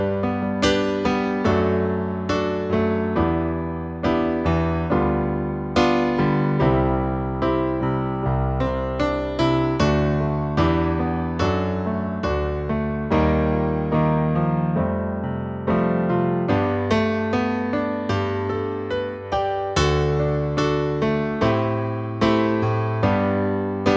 Live Version Pop (1980s) 2:56 Buy £1.50